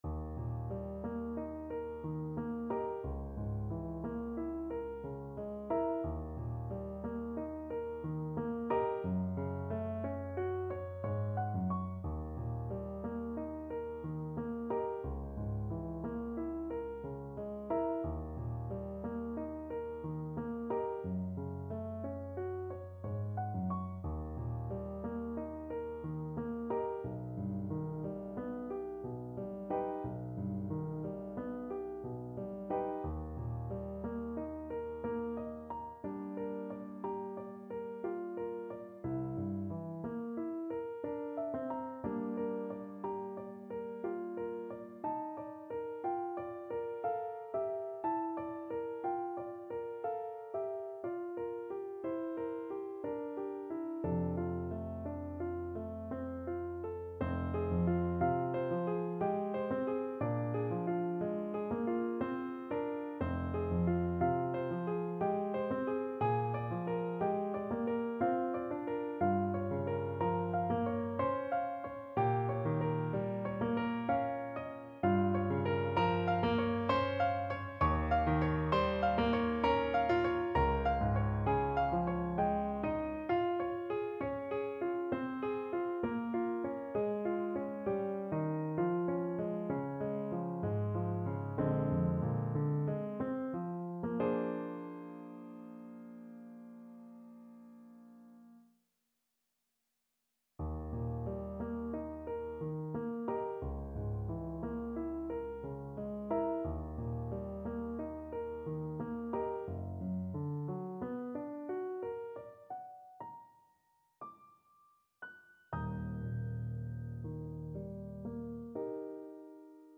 3/4 (View more 3/4 Music)
Andante ma non troppo =60
Classical (View more Classical Trombone Music)